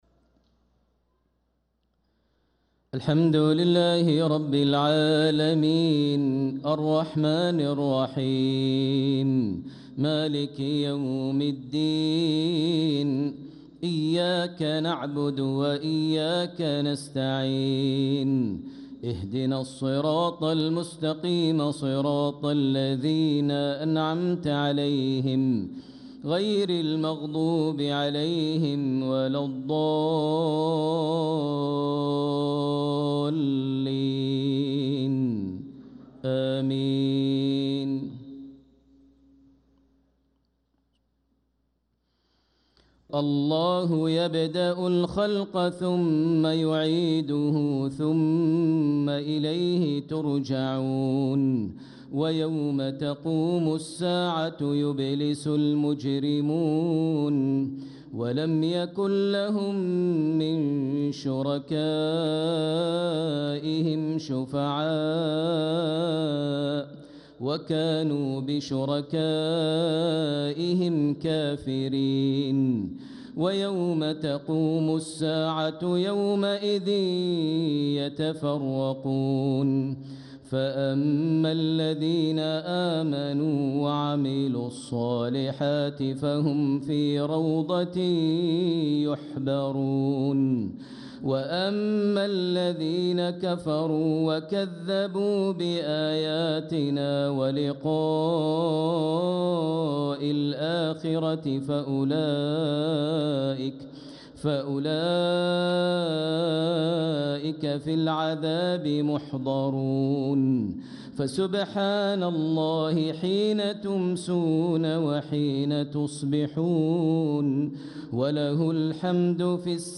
صلاة العشاء للقارئ ماهر المعيقلي 20 رجب 1446 هـ